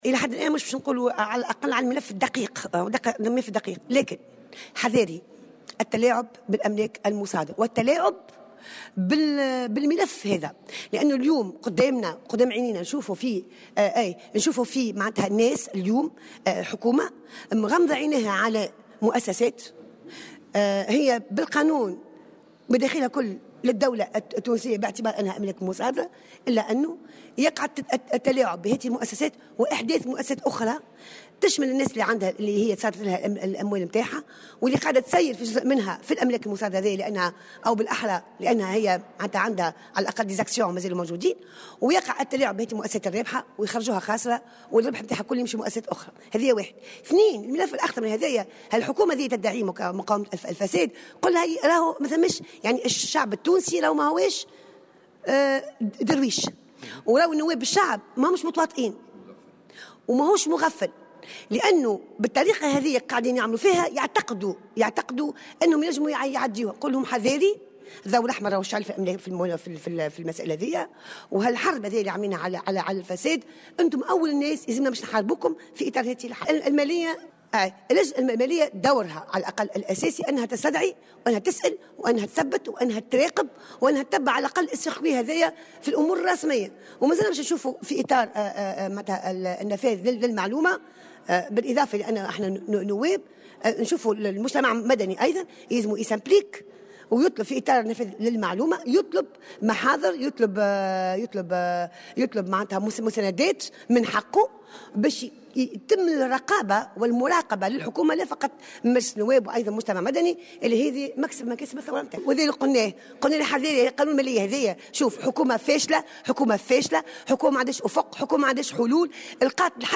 وأضافت في تصريح لمراسل "الجوهرة أف أم" أنه يجب العمل على تعزيز الدور الرقابي ودعم دور المجتمع المدني من خلال تفعيل حق النفاذ إلى المعلومة للتثبت والتحقق من سير هذا الملف.